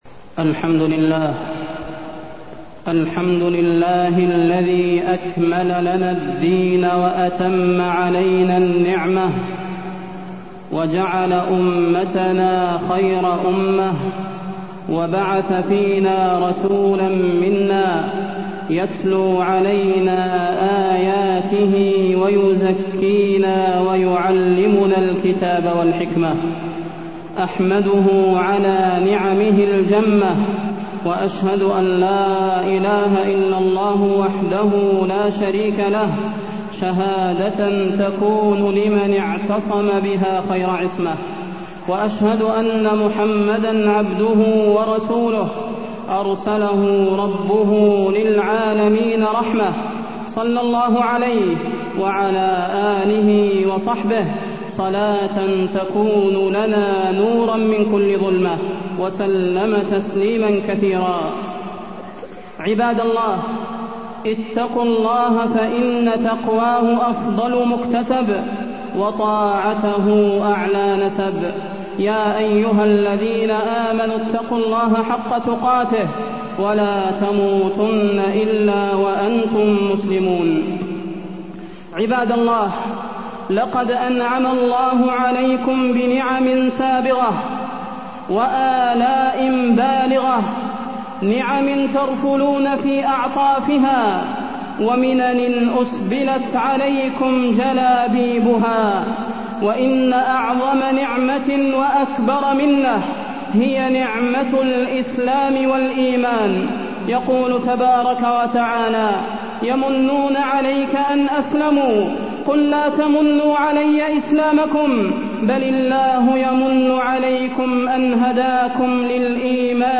تاريخ النشر ٢٣ رجب ١٤٢١ المكان: المسجد النبوي الشيخ: فضيلة الشيخ د. صلاح بن محمد البدير فضيلة الشيخ د. صلاح بن محمد البدير أهمية الصلاة والمحافظة عليها